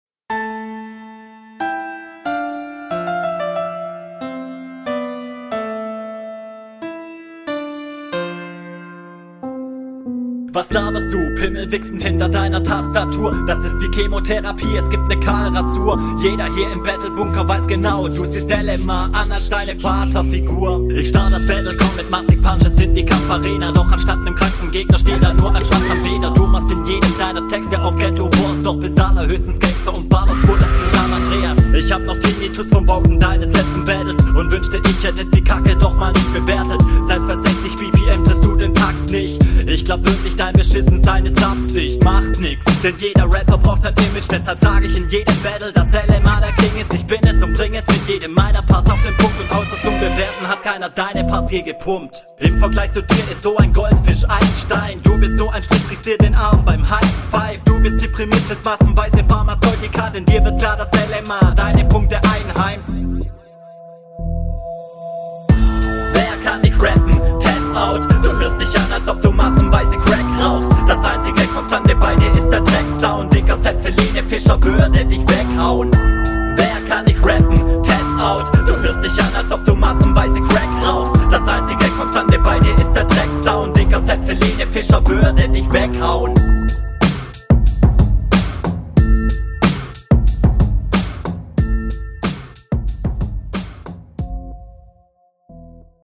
Solide HR, vorallem dein Flow ist einfach mega stark.